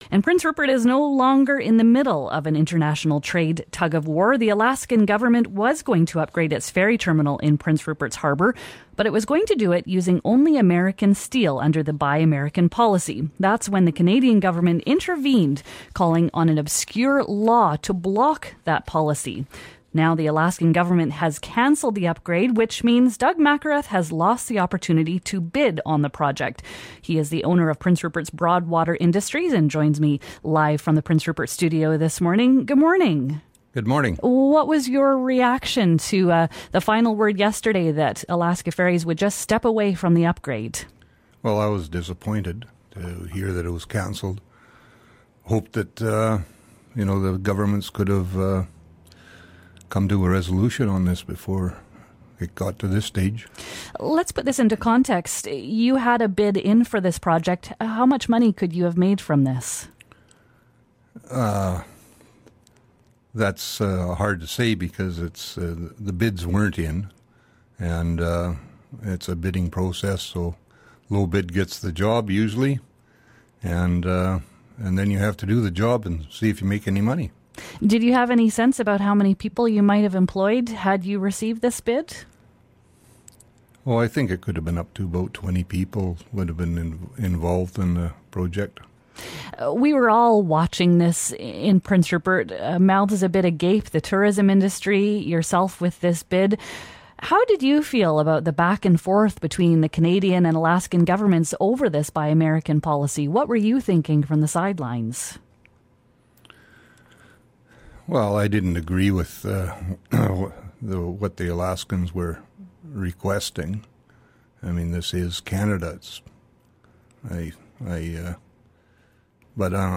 He joined us live in our Prince Rupert studio.